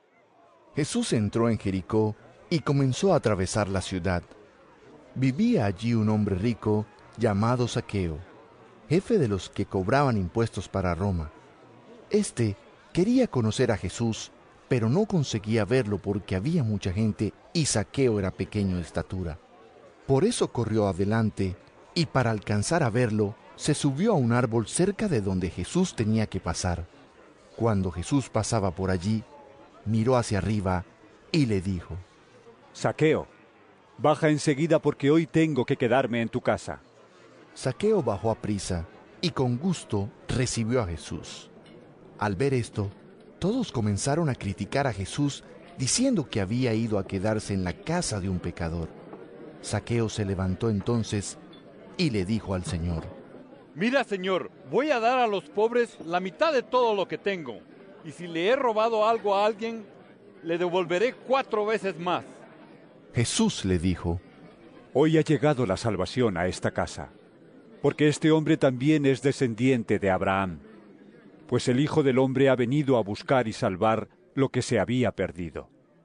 Lc 19 1-10 EVANGELIO EN AUDIO